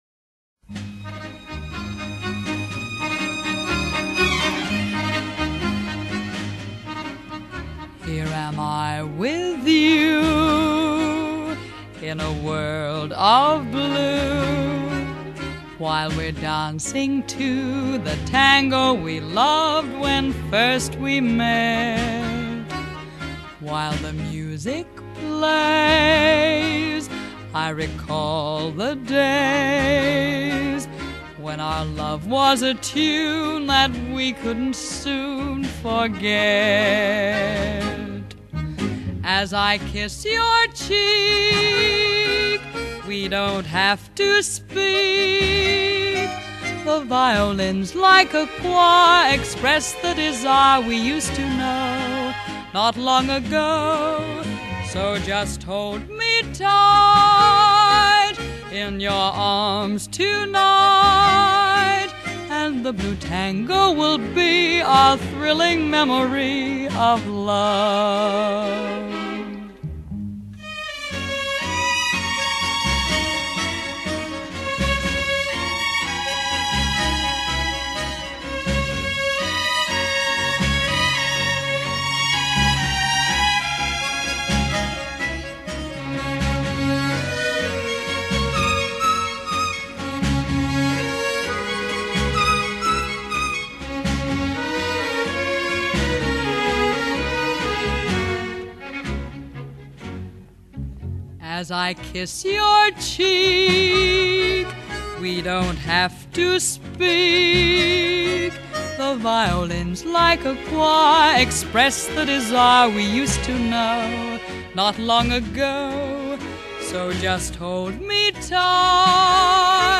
Genre : Pop, Oldies